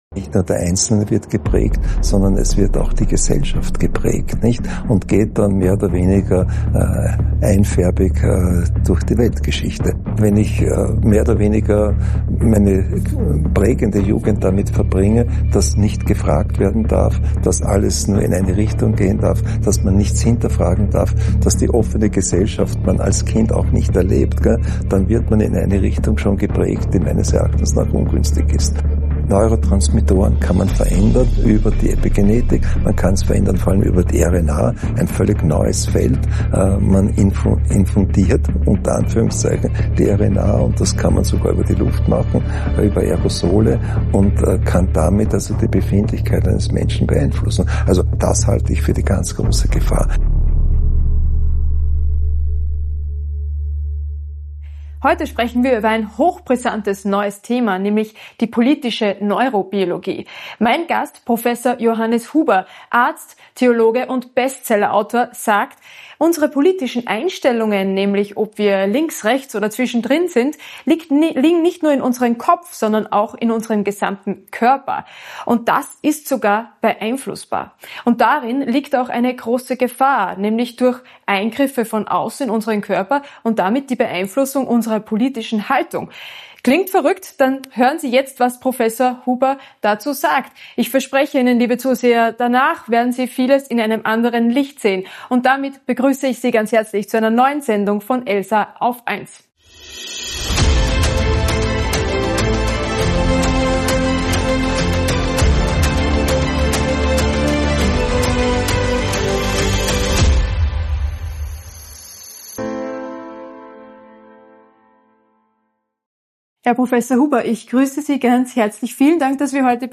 Theologen und Bestsellerautor Prof. Johannes Huber in seiner Praxis interviewt.